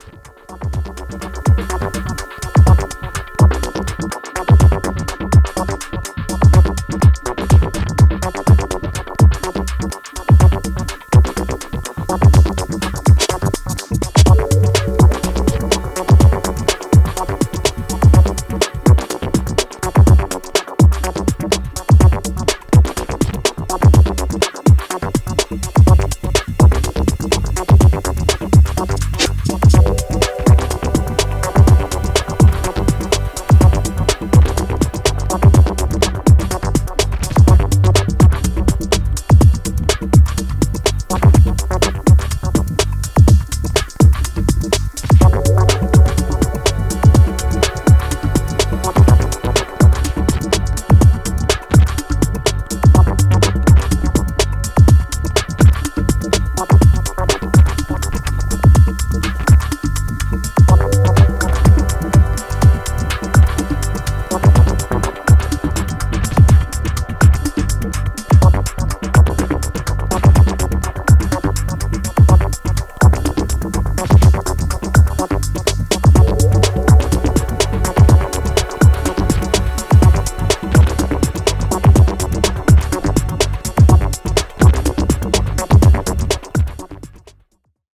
ブロークンなリズムが先導する静的アシッド・ディープ・ハウス